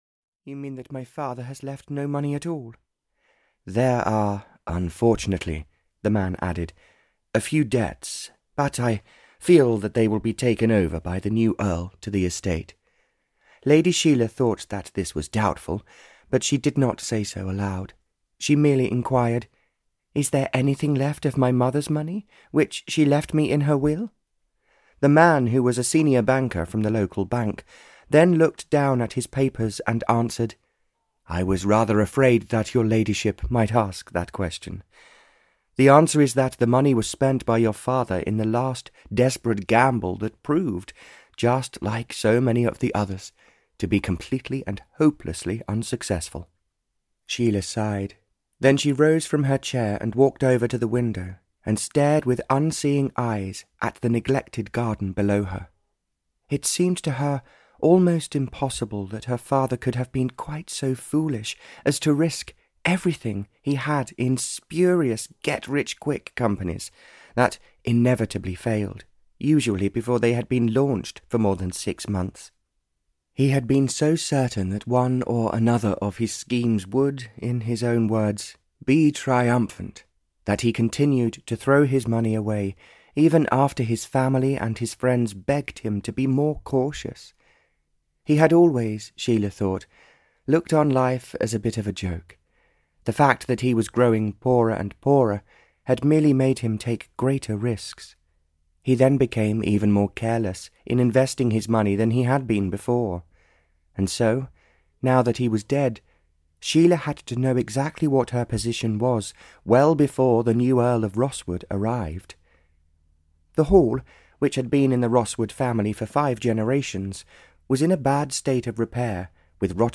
Love Solves the Problem (EN) audiokniha
Ukázka z knihy